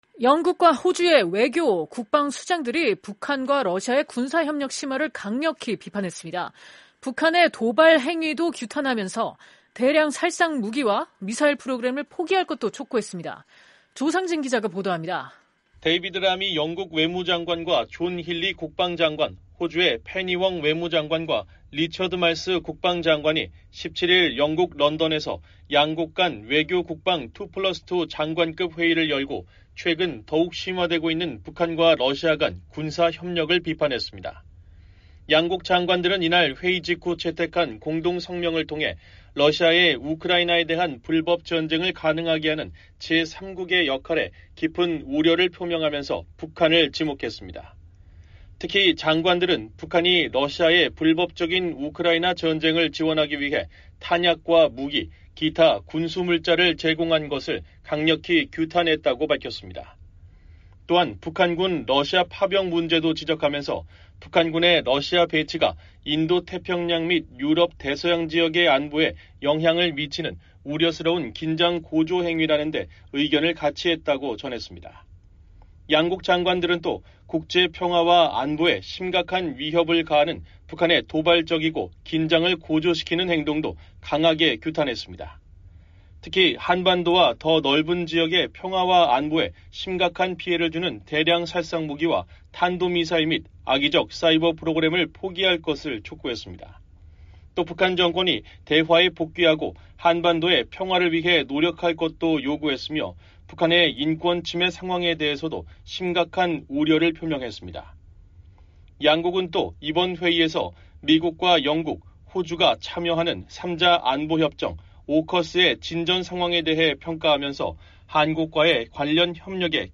속보